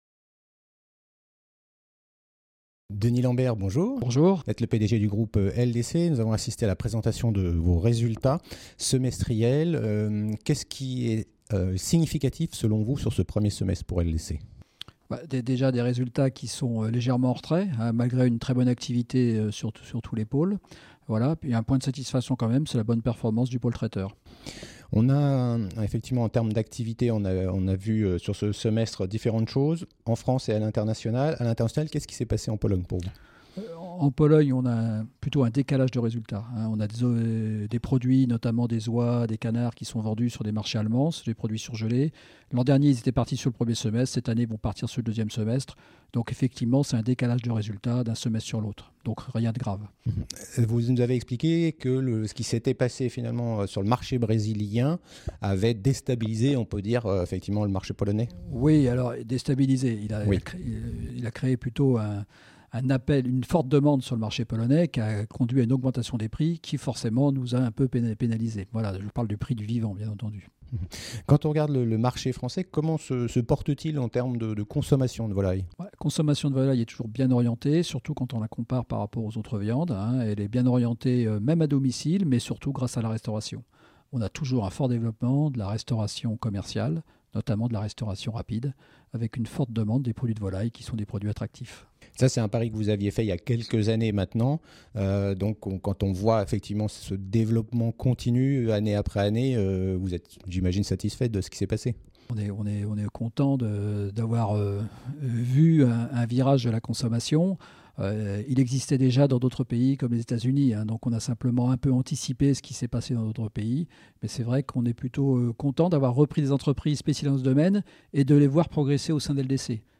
Vous vous intéressez à la société LDC, retrouvez l’ensemble des interviews déjà diffusés sur la Web Tv via ce lien : Vidéos LDC